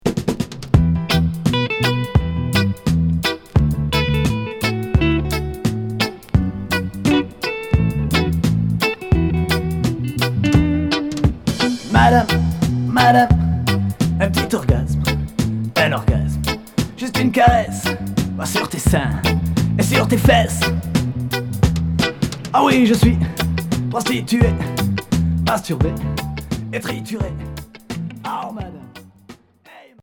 Rock reggae